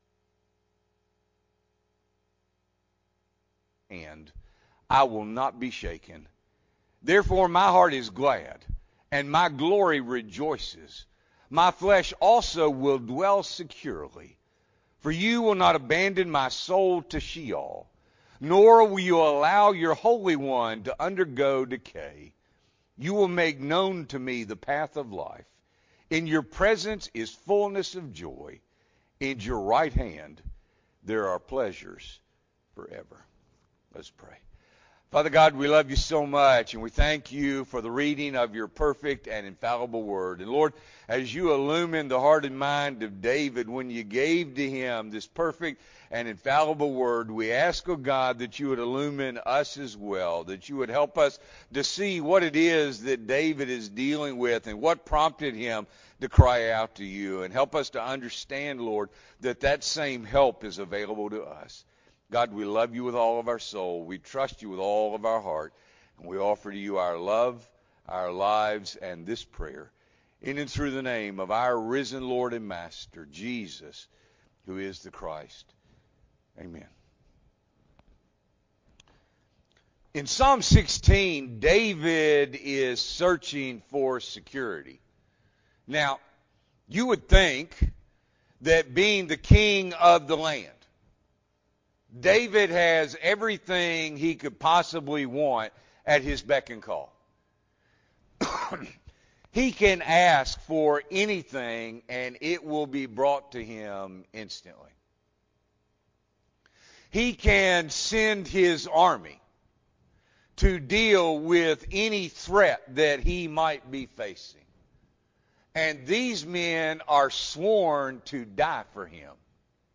October 10, 2021 – Evening Worship